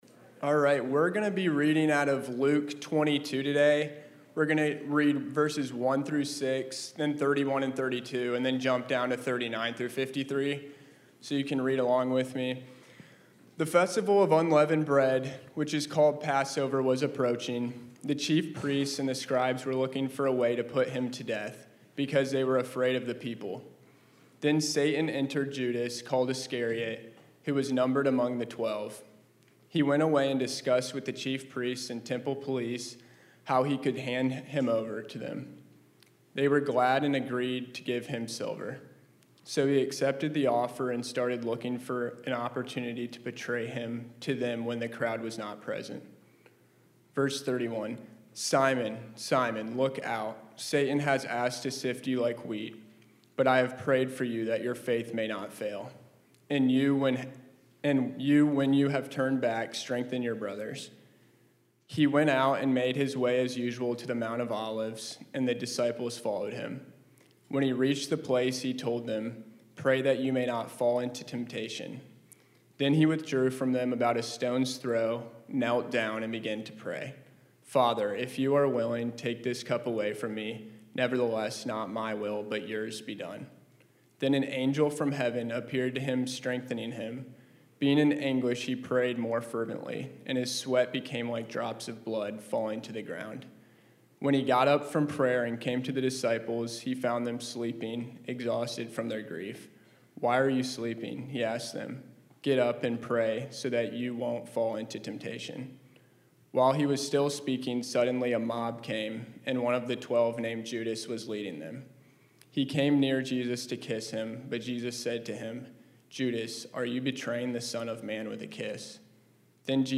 2026-03-29-Service-Sermon-Finishing-Strong-Through-Prayer.mp3